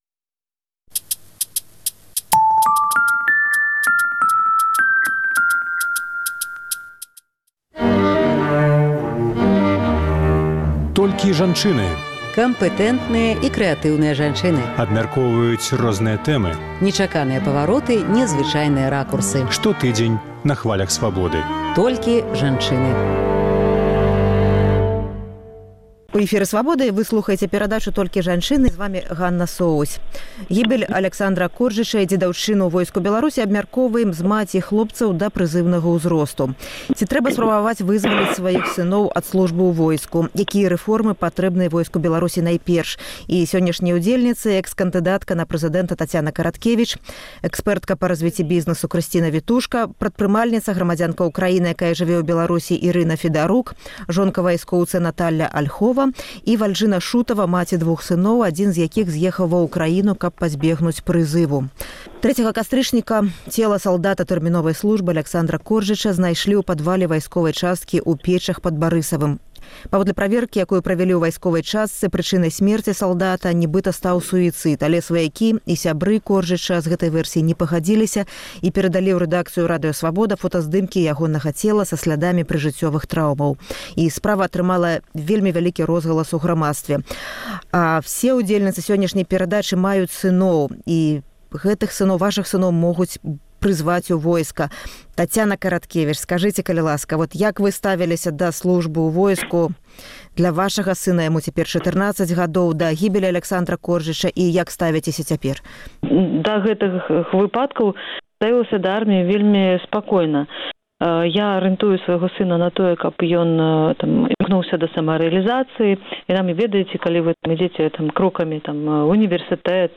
Kампэтэнтныя і крэатыўныя жанчыны абмяркоўваюць розныя тэмы, нечаканыя павароты, незвычайныя ракурсы.